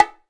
080 - BongoHi.wav